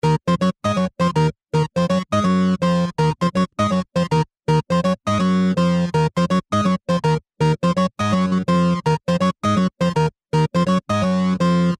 8 bit Gaming Musik
Tempo: schnell / Datum: 15.08.2019